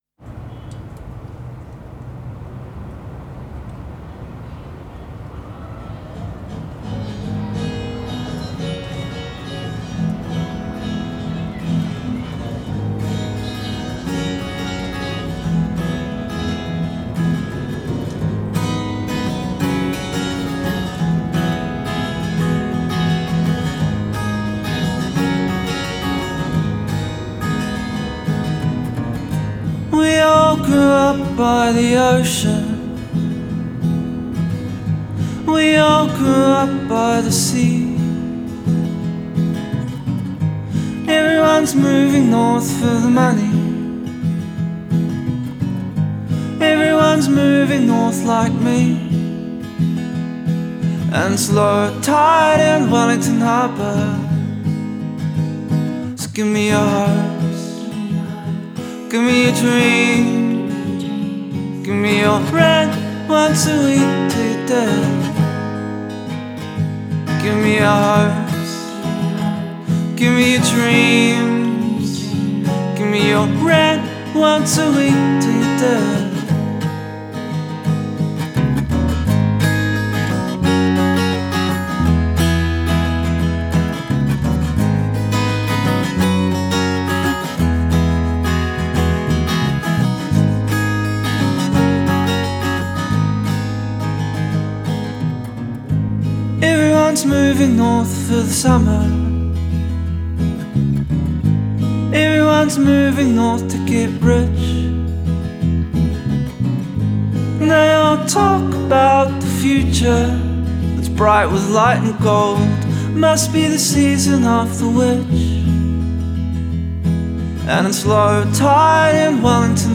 ultra dépouillé et vibrant